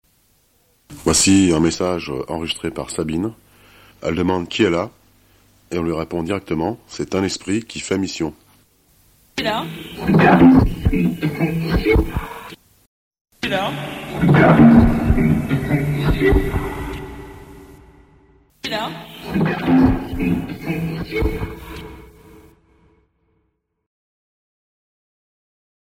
J’utilise des ondes Allemandes ou slaves (non latines) stables, non brouillées.
Exemples de Transcommunication instrumentale (T.C.I.)